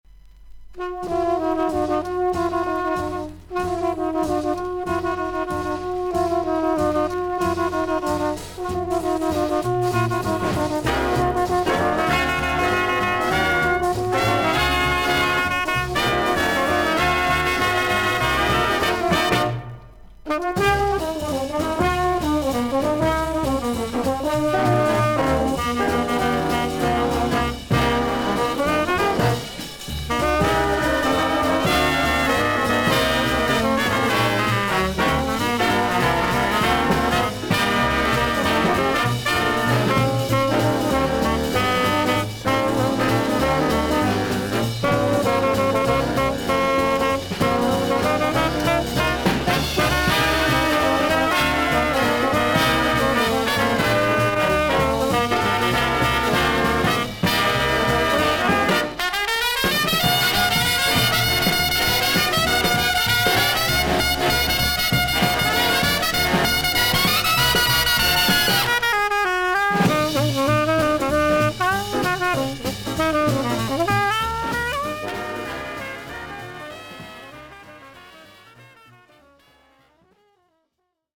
ジャズ・トランペッター/アレンジャー。
ビシバシ決まるビッグ・バンド・ジャズ。
VG++〜VG+ 少々軽いパチノイズの箇所あり。クリアな音です。